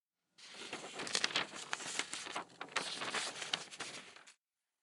Foley
Rustling Paper 3 Sound Effect
Keywords and related variations include foley, paper, one-shot.